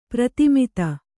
♪ pratimita